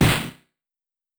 punch_ish_1_bc.wav